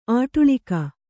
♪ āṭuḷika